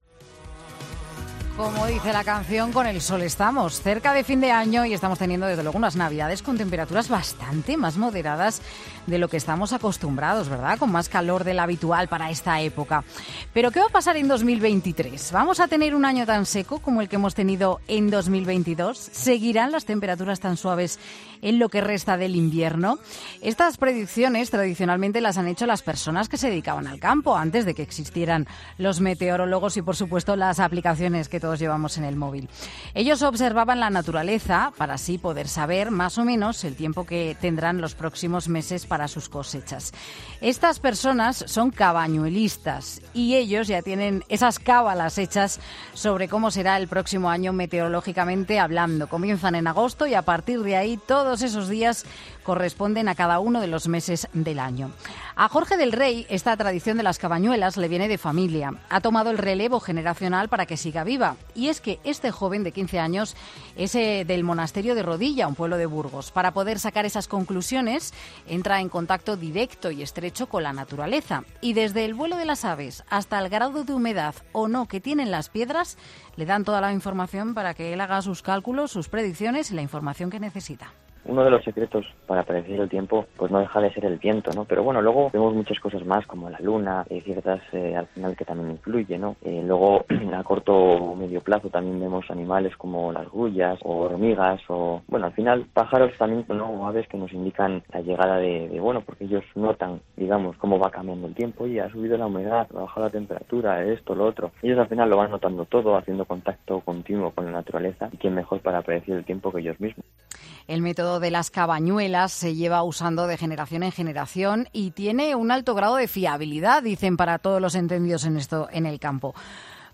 En 'Mediodía COPE' hablamos con dos cabañuelistas que nos cuentan qué tiempo podemos esperar para el 2023 y será muy distinto a lo que hemos tenido en este año